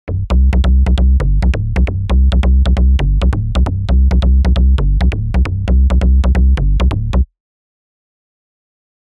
The track should be assigned to a bass sound.
mbseqv4_tut2_2.mp3